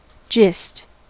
gist: Wikimedia Commons US English Pronunciations
En-us-gist.WAV